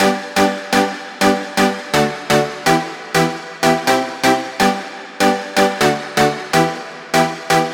ここでは変化がわかりやすいように普通のPluck系EDMっぽいリードサウンドを使用します。
まずこちらが何もやってない状態でプリセットを読み込んだ音。
1.プラック↓
Nexus-例-プラック成功例.mp3